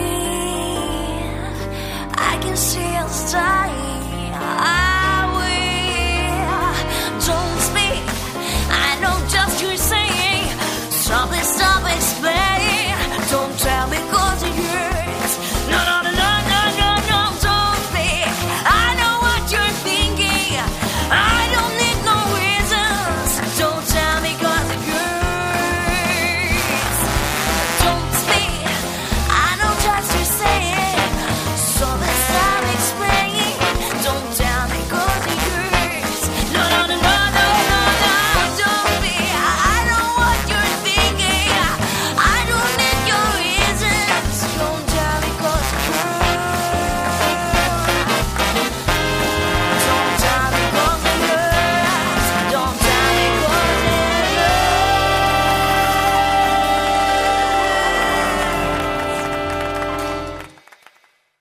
• Качество: 112, Stereo
поп
женский вокал
труба
вживую
jazz-pop
Кавер на популярную песню